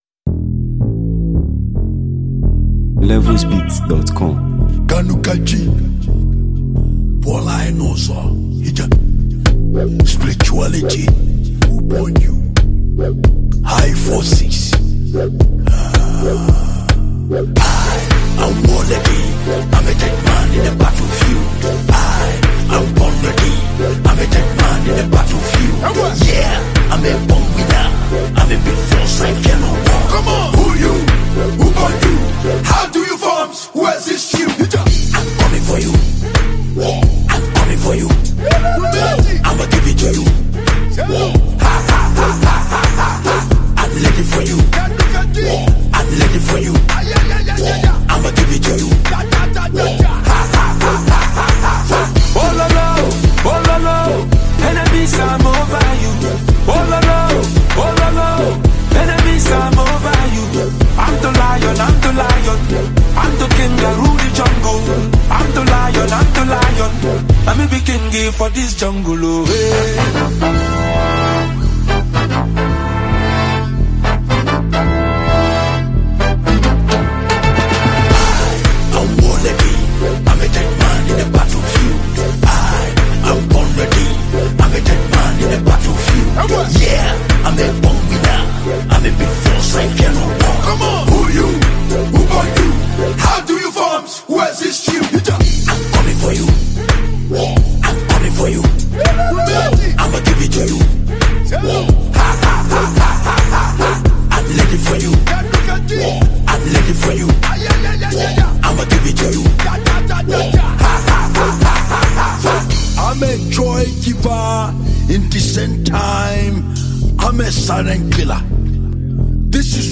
Prestigious Nigerian highlife icon and celebrated songwriter